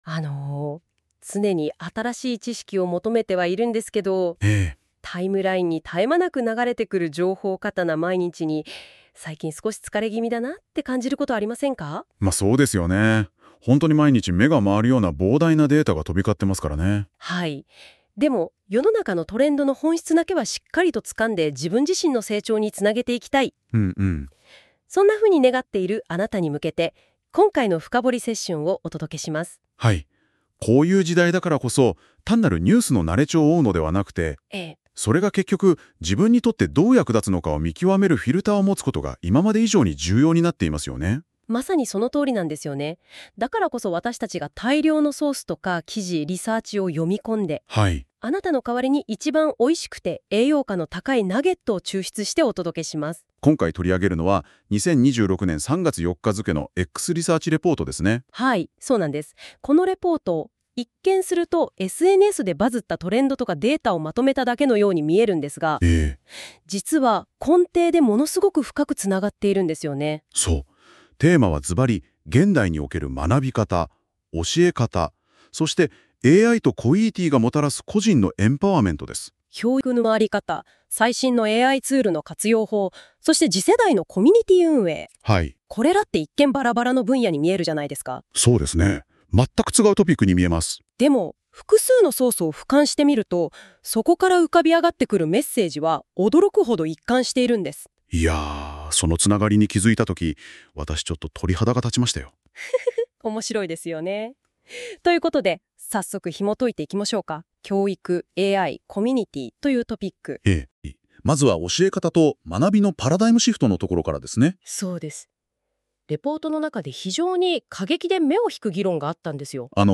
以下は、2026年3月4日のXリサーチレポートをもとにした深掘り対談の書き起こしです。教育・AI・コミュニティの3つのトレンドが、実は1つのメッセージに収束していく過程をお楽しみください。